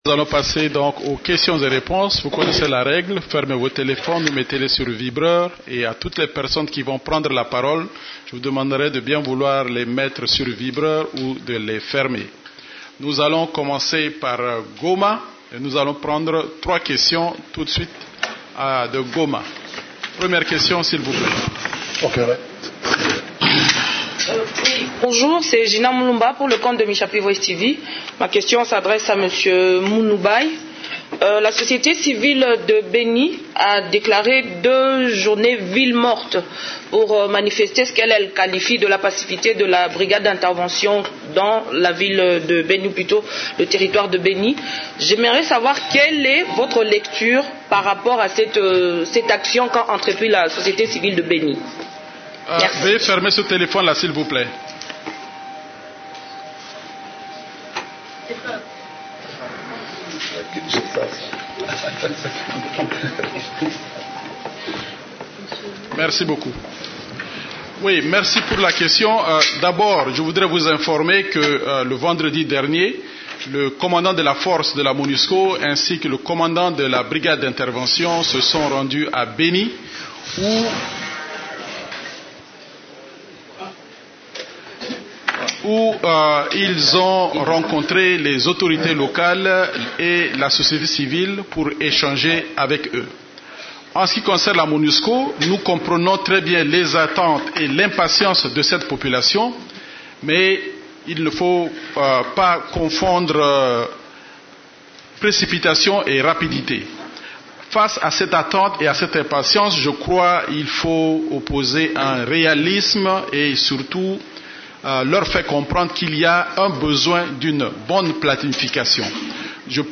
La conférence de presse hebdomadaire des Nations unies en RDC du mercredi 21 août a porté sur les sujets suivants: